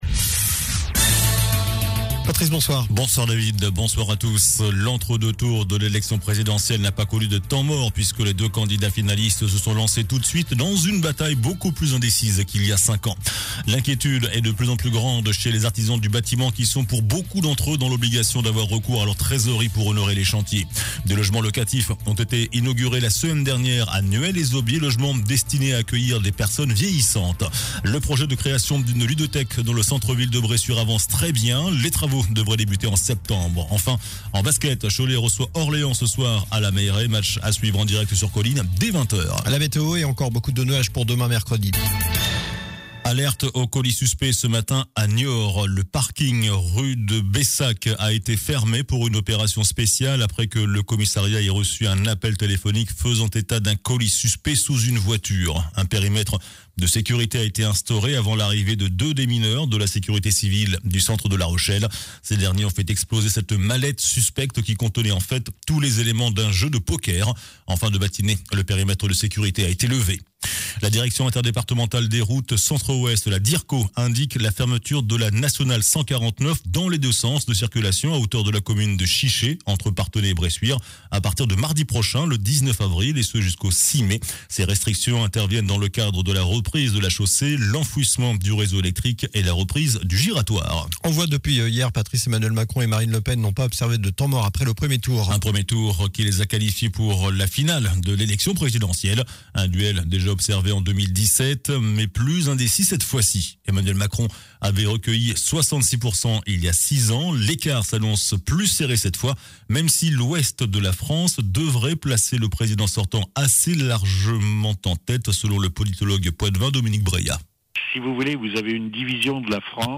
JOURNAL DU MARDI 12 AVRIL ( SOIR )